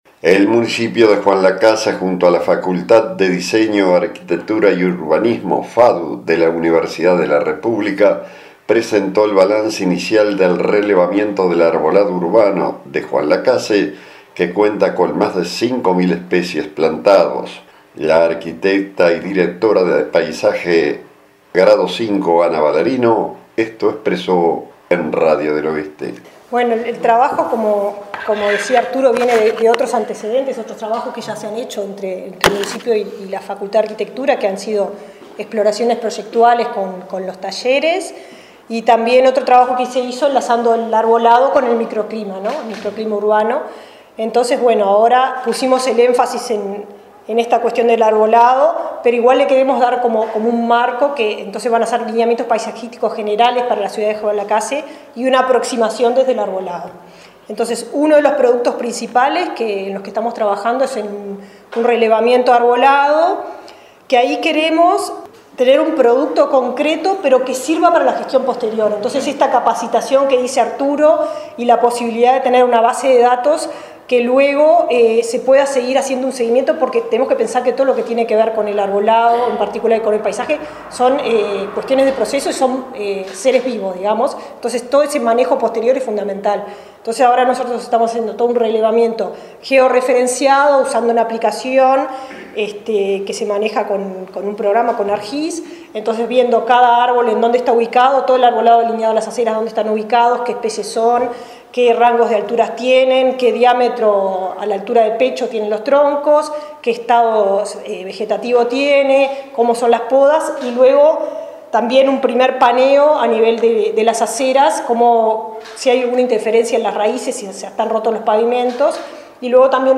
habló en Radio del Oeste sobre el trabajo que se viene realizando.